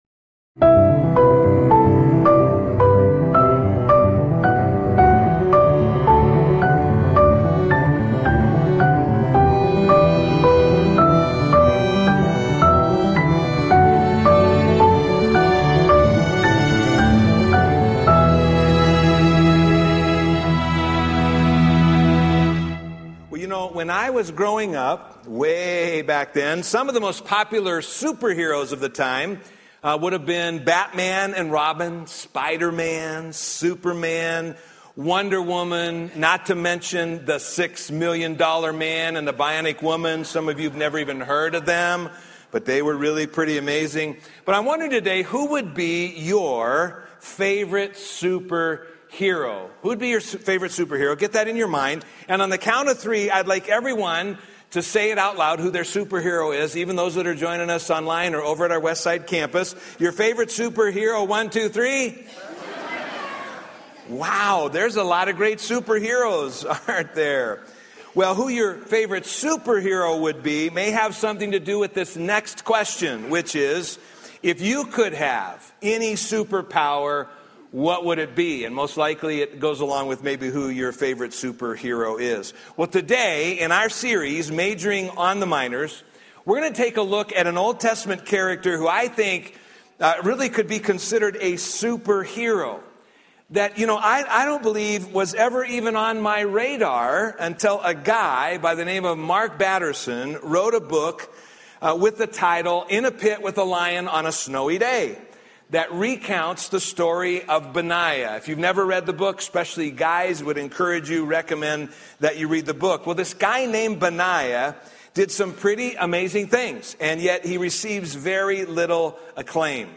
A message from the series "Majoring on the Minors."